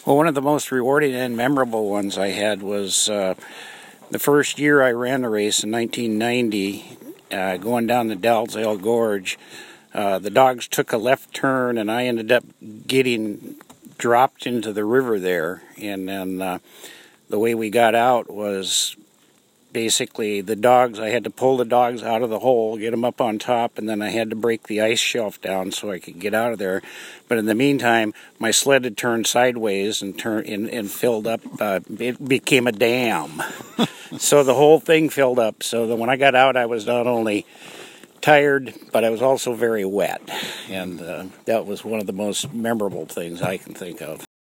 Temperature: 10F / OUTDOORS